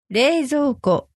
noun | れいぞうこ